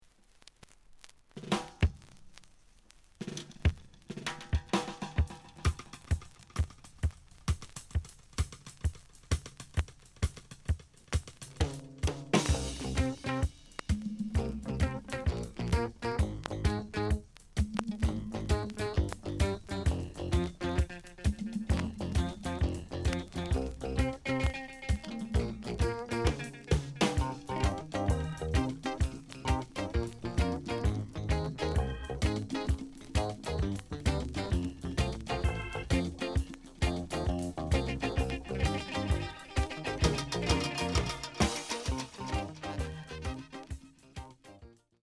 The audio sample is recorded from the actual item.
●Genre: Reggae